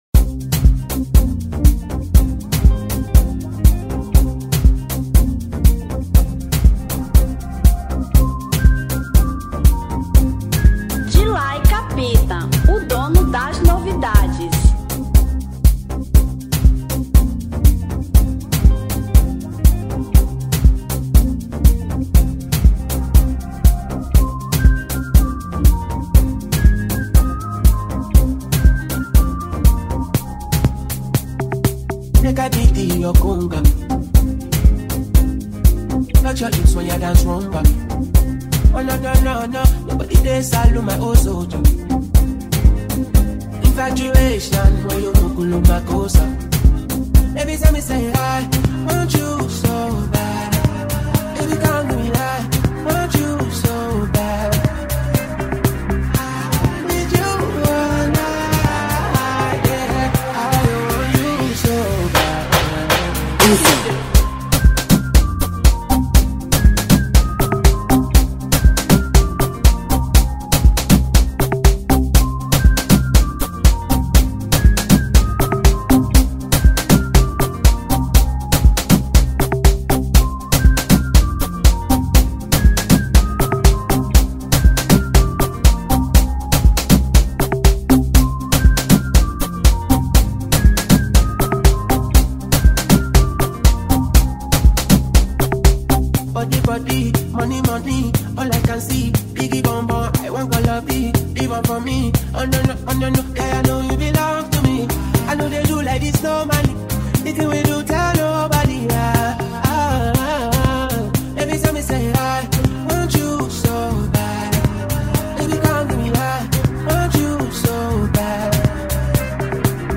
Remix 2024